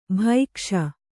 ♪ bhaikṣa